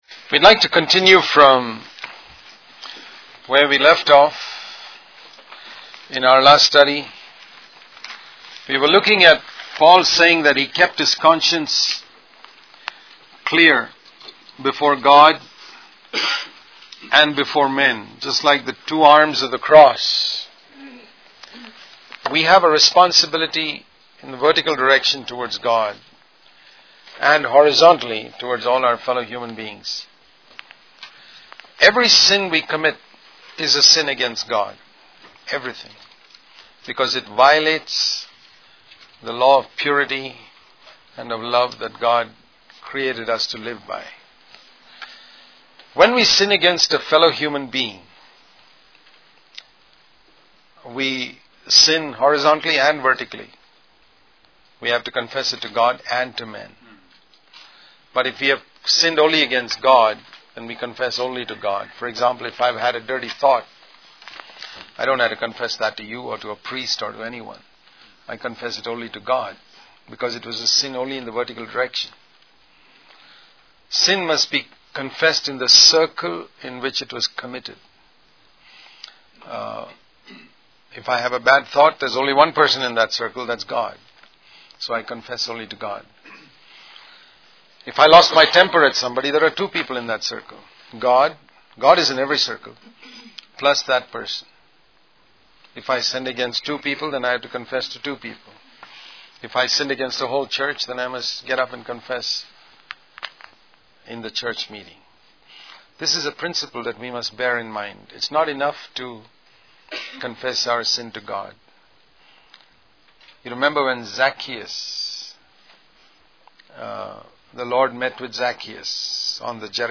In this sermon, the speaker emphasizes the importance of setting one's past life right before entering the house of God. He encourages listeners to make restitution for any wrongs they have committed, specifically in regards to stolen money.